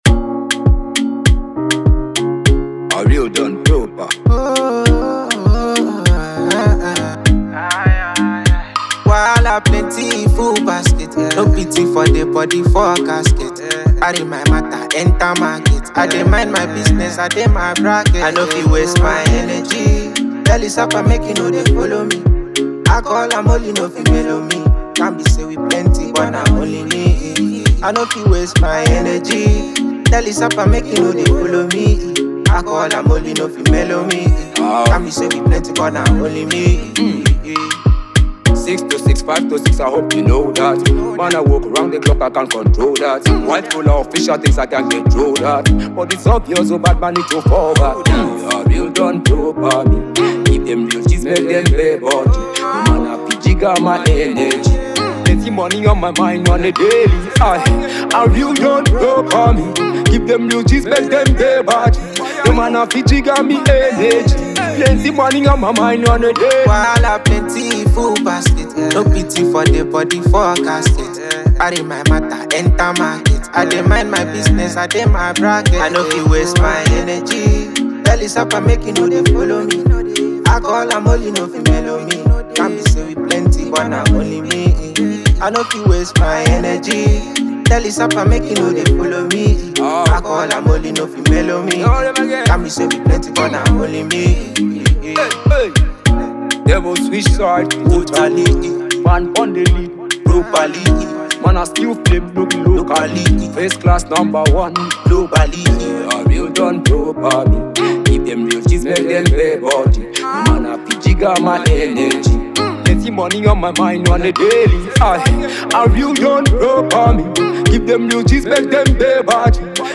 When it comes to Reggae Dancehall music
Raggae Dancehall